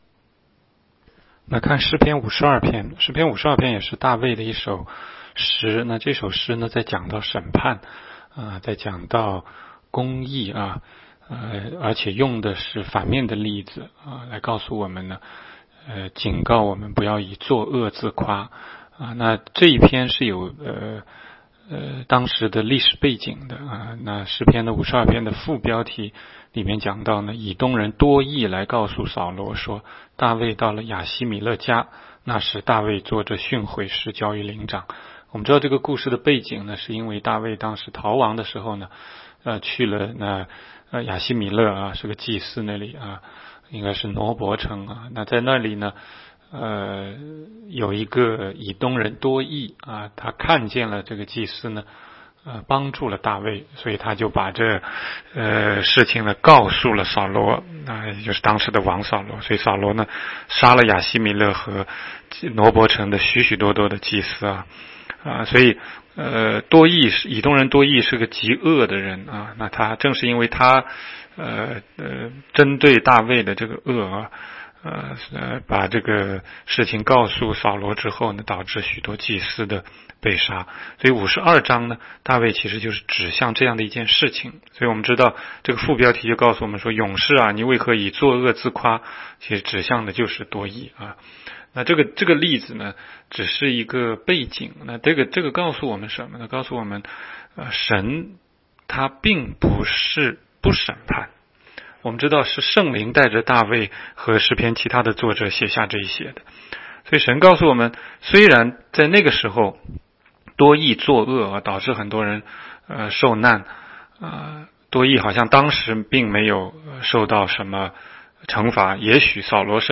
16街讲道录音 - 每日读经-《诗篇》52章